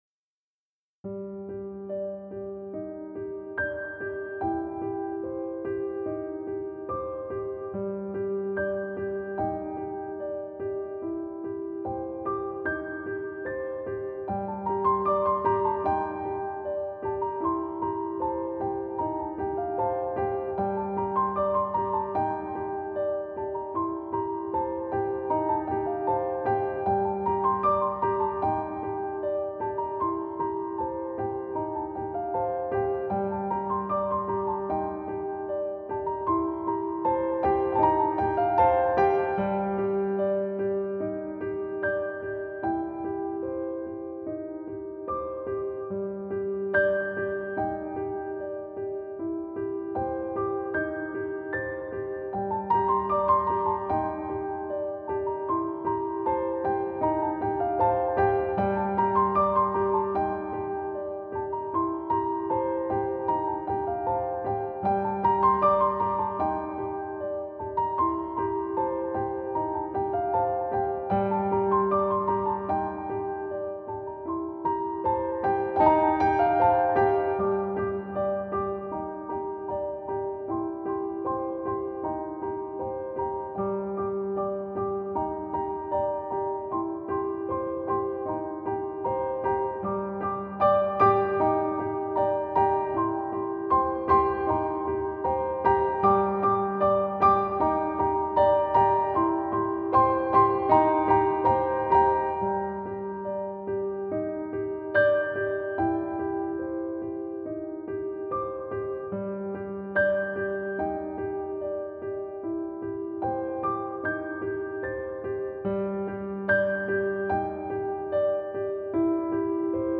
Piano arrangement